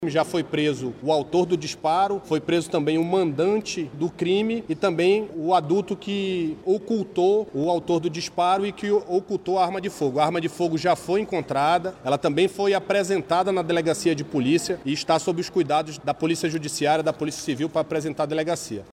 O subcomandante ressalta que os principais envolvidos no crime já estão presos.